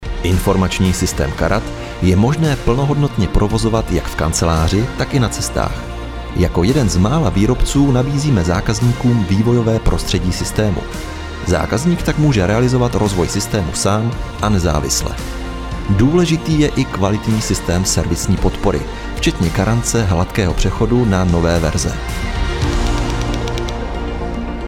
Umím: Voiceover
Mužský voiceover do Vašich videí